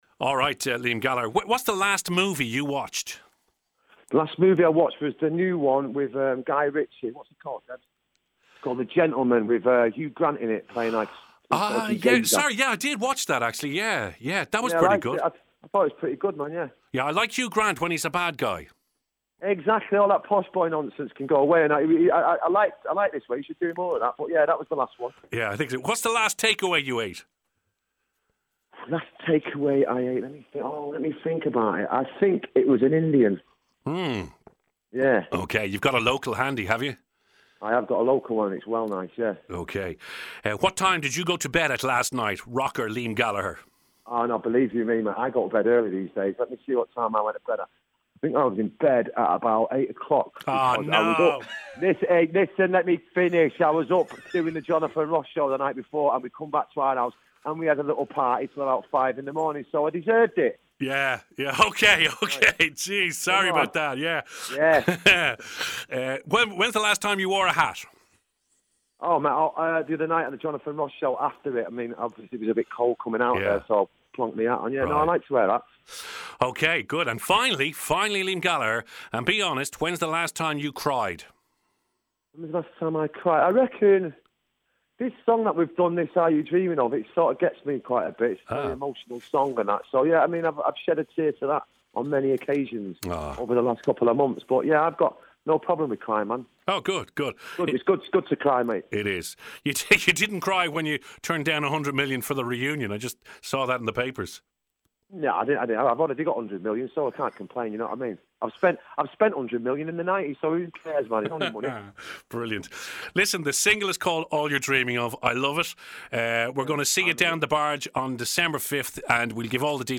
Speaking exclusively to Radio Nova, Liam Gallagher has revealed he records music in his boxer shorts.